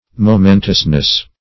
momentousness \mo*men"tous*ness\ n.